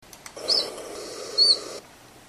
This direct comparison and the sonograms illustrate the relatively abrupt transition of the collybita call and the smoother transition of the tristis / 'fulvescens' call, together with the differences in peak frequency and frequency range, which create a different timbre. Yet, both are clearly ‘sweeoo’ calls to the ear and could well be confused if heard in isolation or in the imperfect acoustic conditions which will generally attend field encounters.